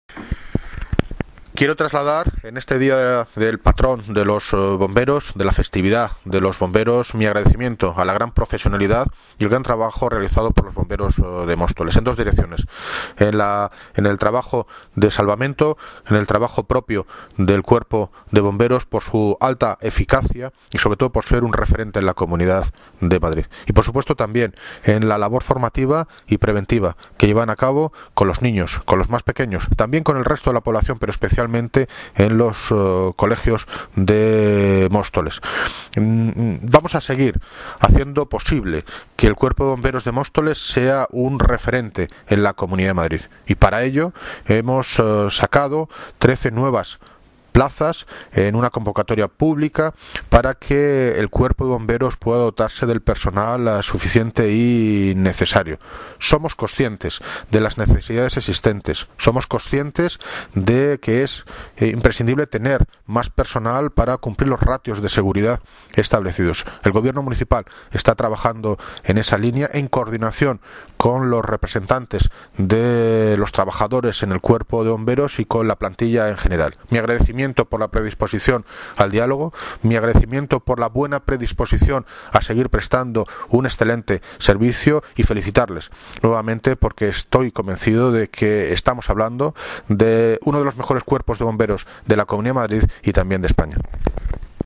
Audio - David Lucas (Alcalde de Móstoles) Sobre Patrón Bomberos